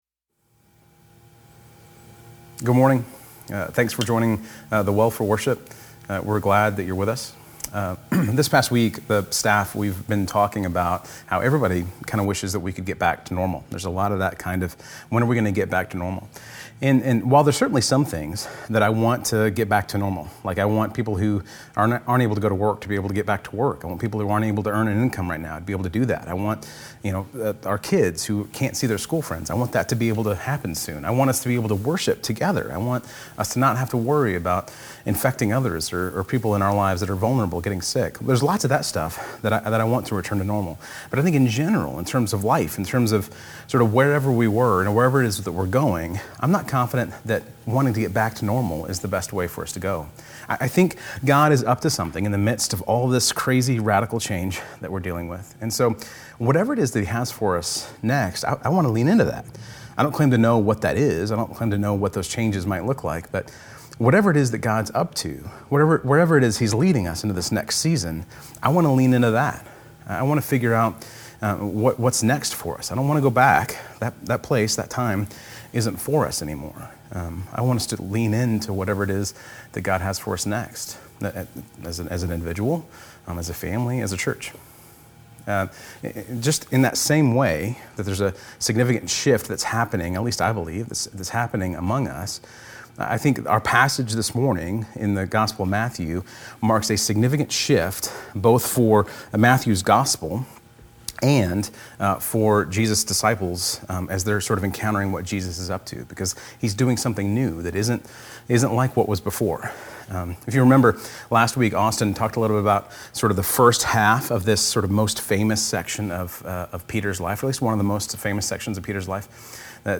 The-Well-Sermon.mp3